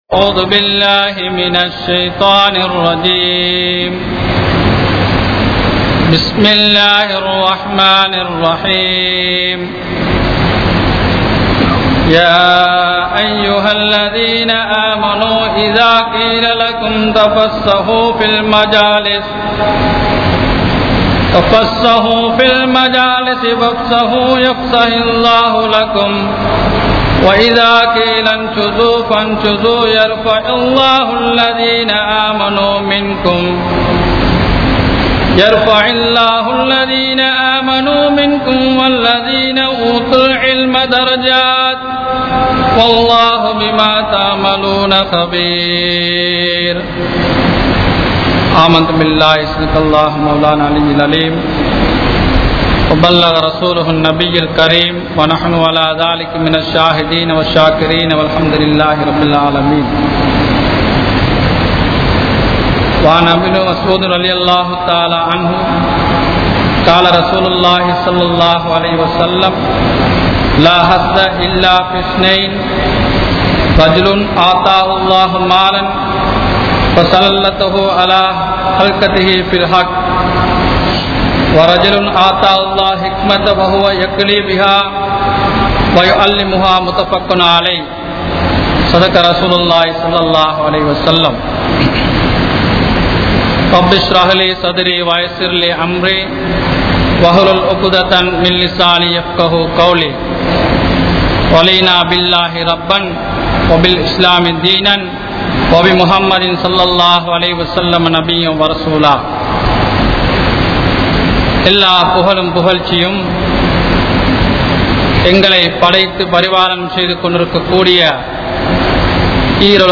Importants of Education | Audio Bayans | All Ceylon Muslim Youth Community | Addalaichenai